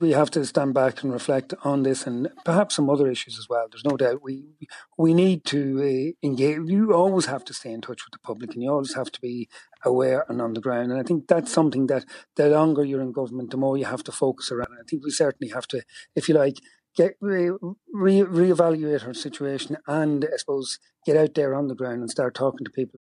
The Midlands North West MEP says the government needs re-evaluate ahead of the local and European election in the coming months: